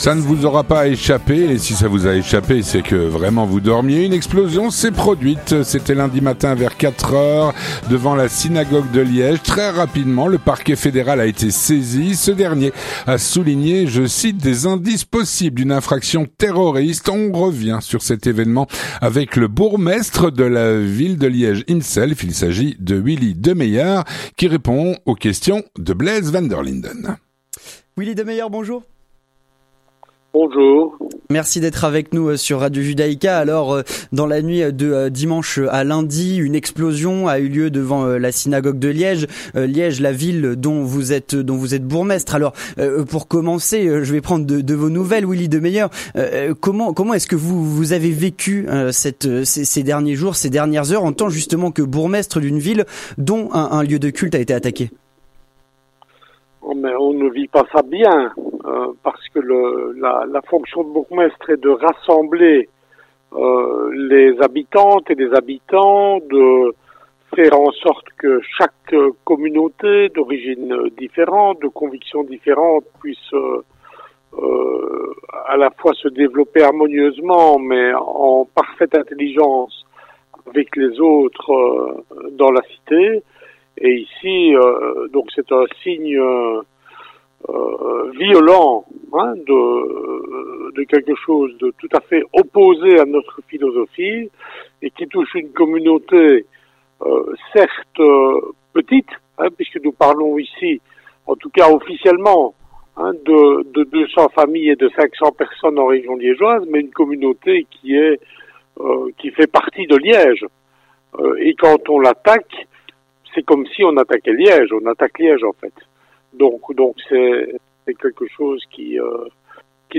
On revient sur cet événement avec Willy Demeyer, bourgmestre de la ville de Liège.